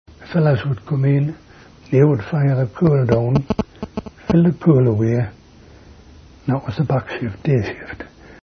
interview
Narrative History